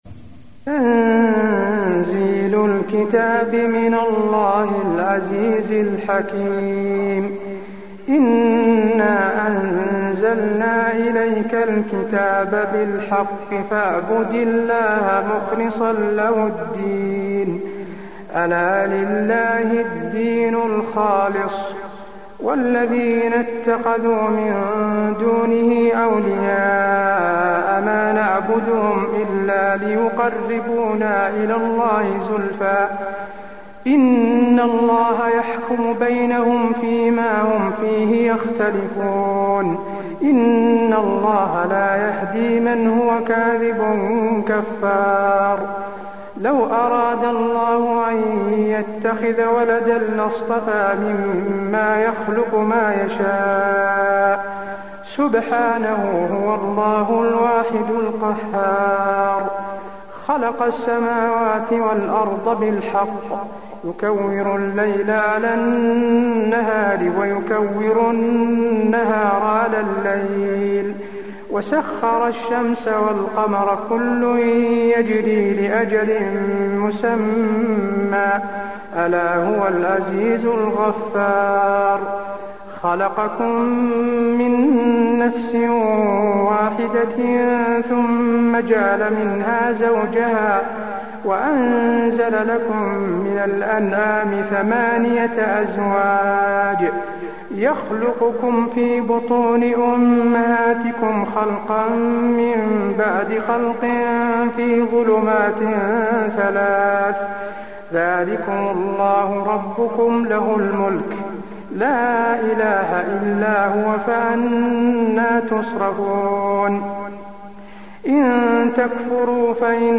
المكان: المسجد النبوي الزمر